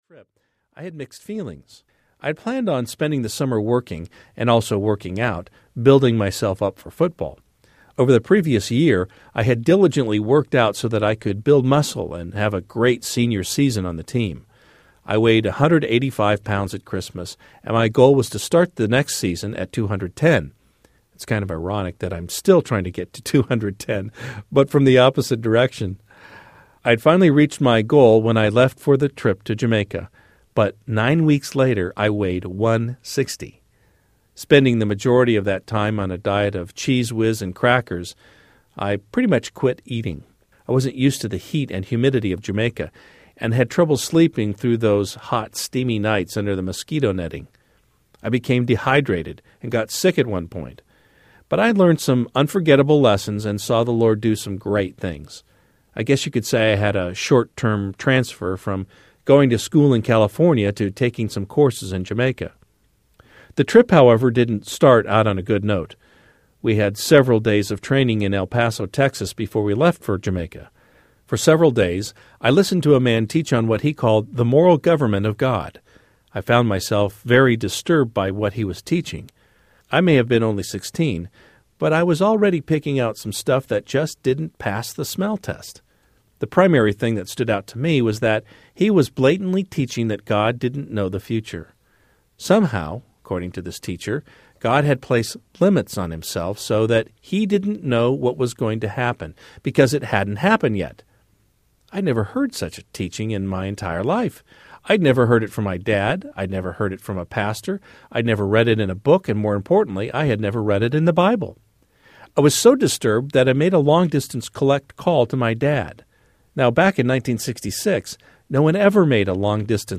True Courage Audiobook
5 Hrs. – Unabridged